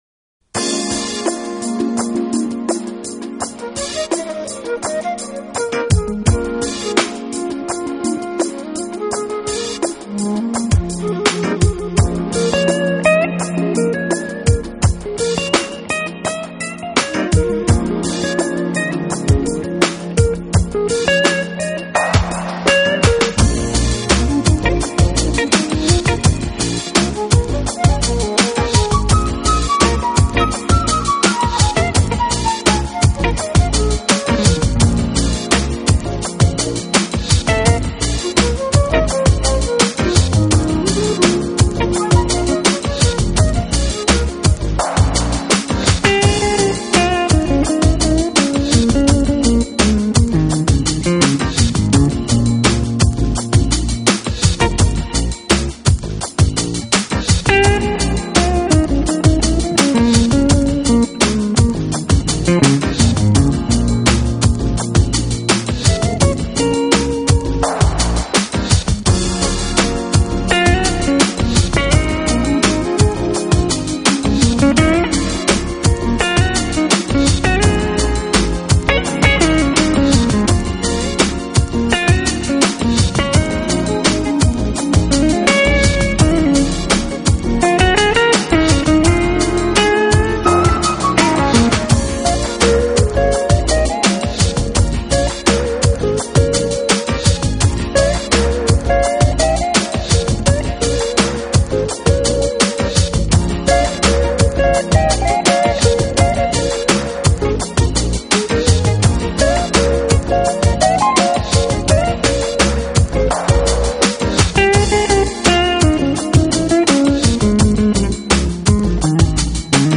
泛滥，动听的旋律和着舒适摇摆的节奏让一切都摇晃起来，这些跳动的音符、